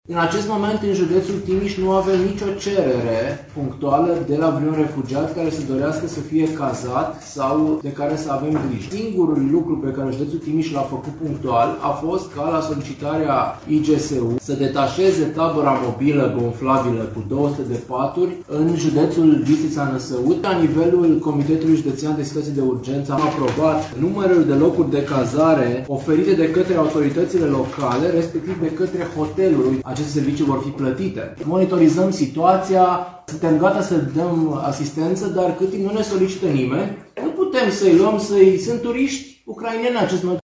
26 de hoteluri sunt dispuse să îi cazeze, iar în județ sunt șase localități care au spații pregătite pentru ei, spune prefectul de Timiș, Mihai Ritivoiu.
Mihai-Ritivoiu-refugiati.mp3